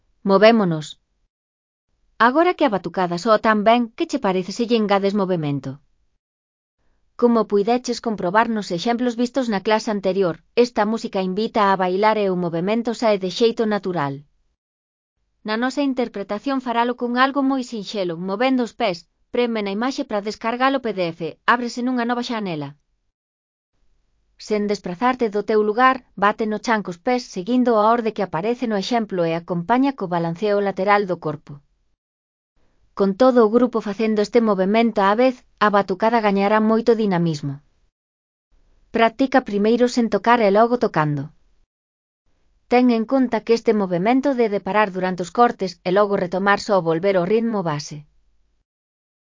Con todo o grupo facendo este movemento á vez, a batucada gañará moito dinamismo.
Ten en conta que este movemento debe parar durante os cortes e logo retomarse ao volver ao ritmo base.